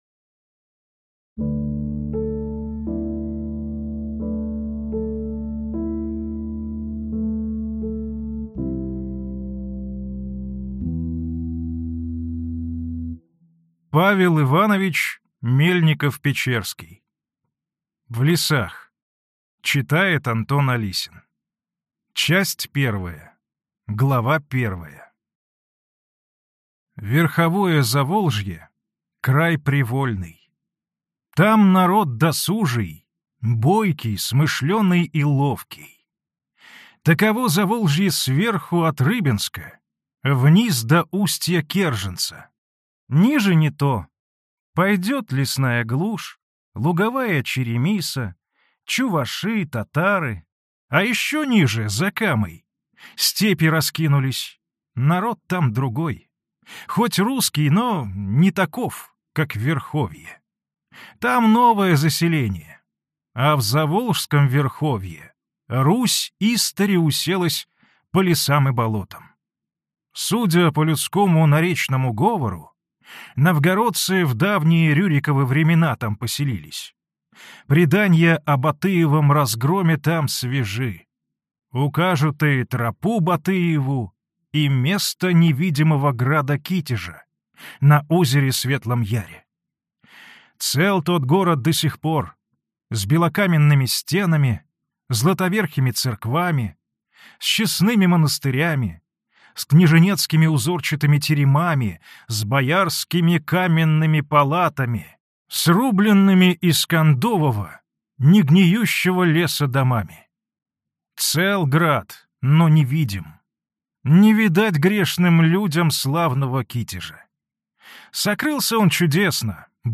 Аудиокнига В лесах | Библиотека аудиокниг